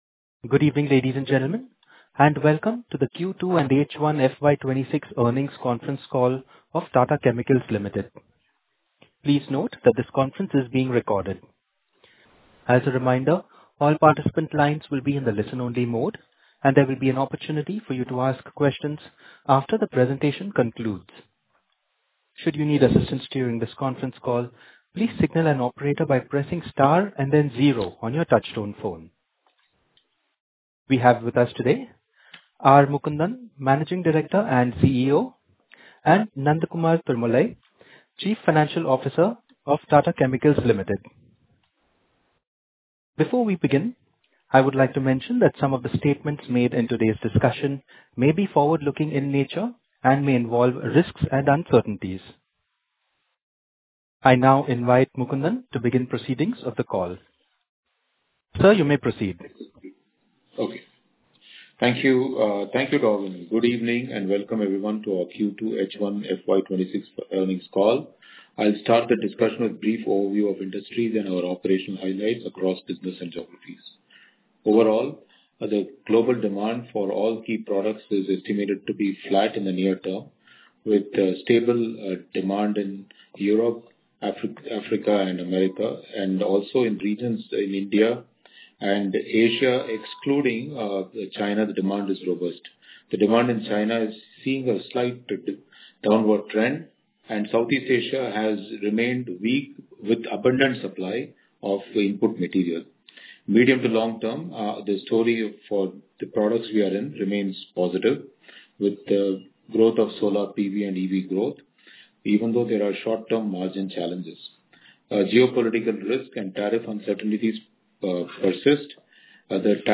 Investor Call - Audio Recording
q2-and-h1fy26-earnings-call-audio-transcript_0.mp3